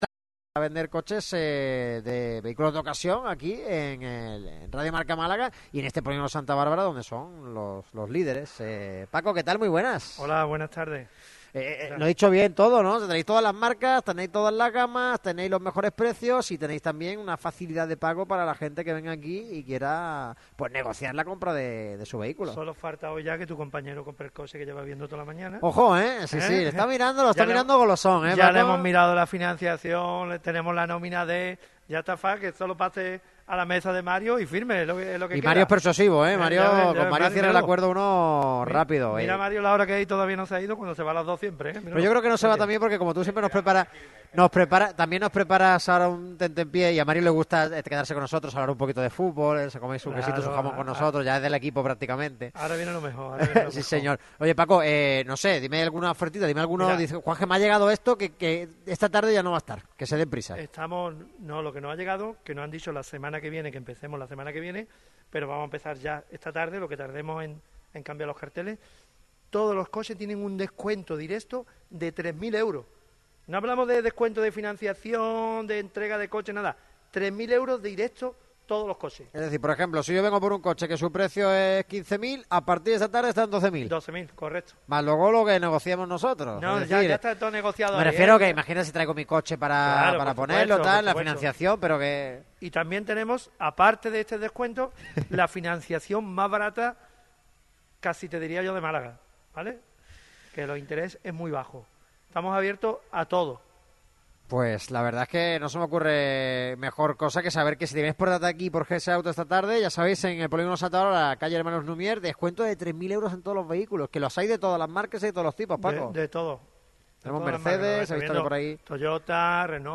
Este miércoles, el equipo ha visitado las instalaciones de GS Autos en el Polígono de Santa Bárbara, C/ Hnos Lumiere 17.